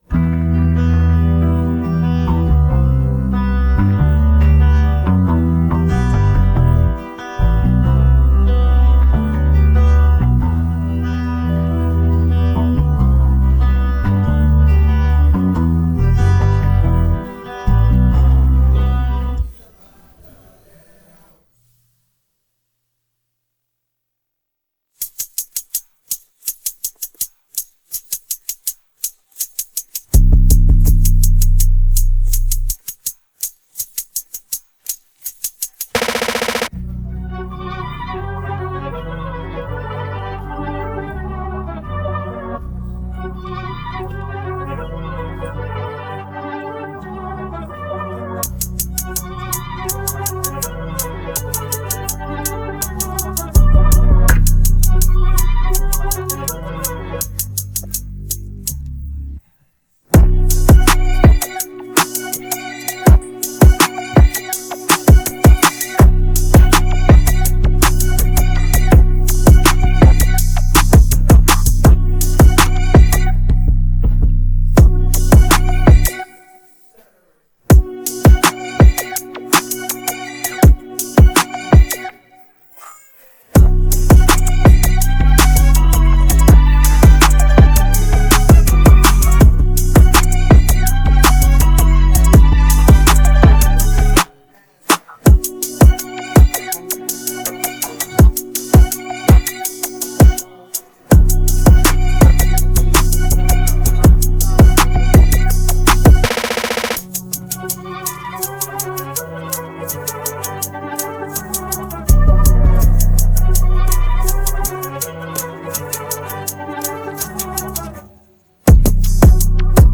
Hip hophiphop trap beats
a fusion of smooth rhythms and impactful melodies